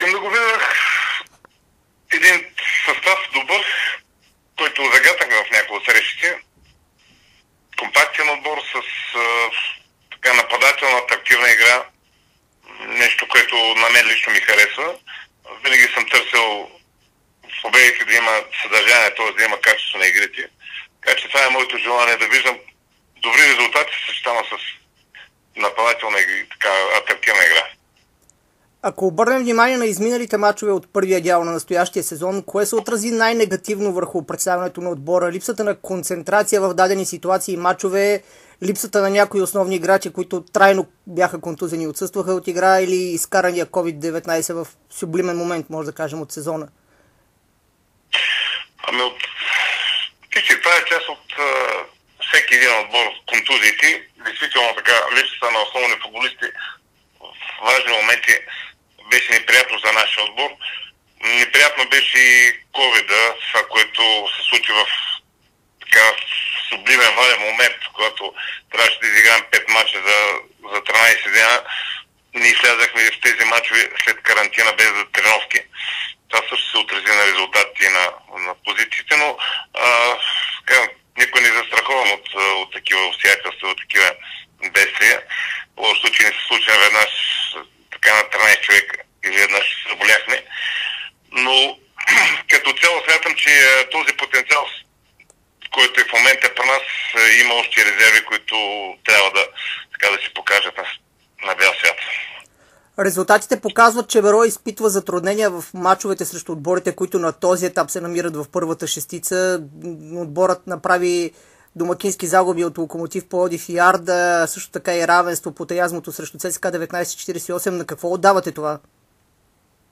интервю